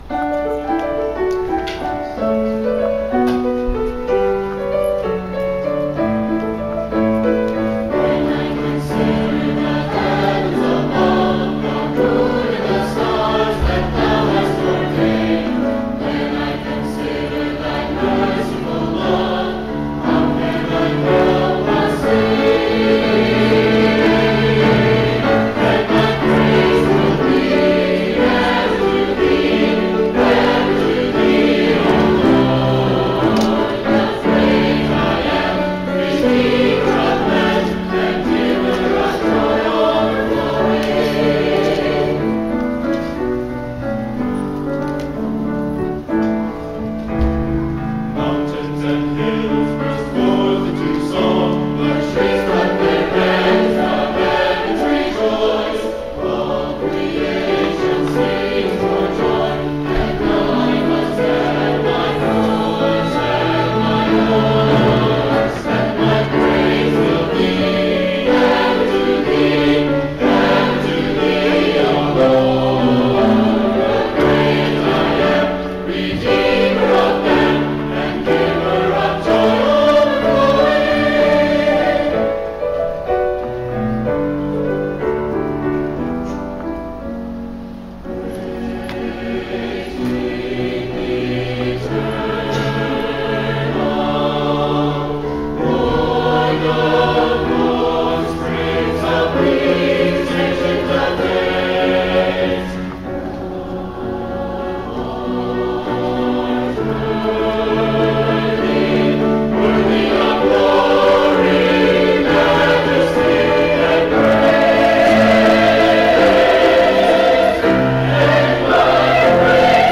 Wyldewood Baptist Church Choir.
Joy Overflowing – SATB